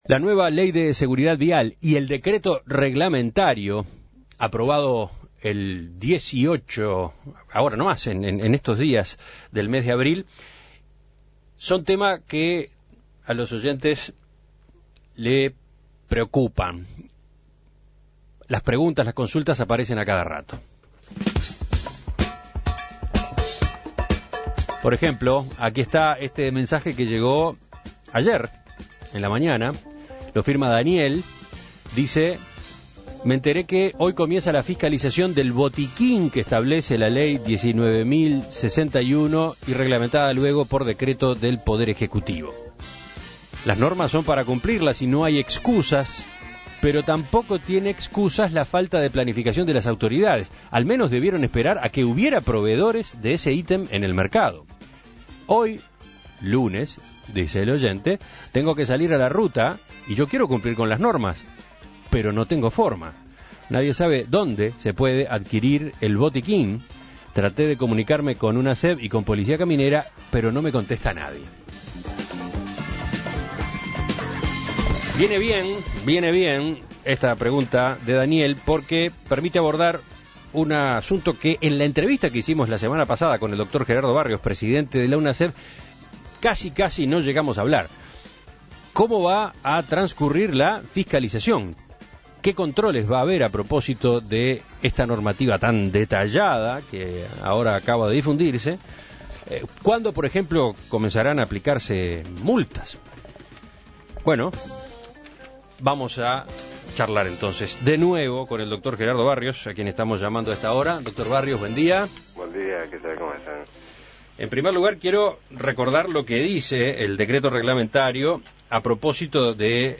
Uno de los puntos en discordia es la incorporación del maletín de seguridad vial previsto en la ley. Para responder a las dudas sobre este nuevo elemento que debe estar en cada vehículo, En Perspectiva dialogó nuevamente con Gerardo Barrios, presidente de la Unasev.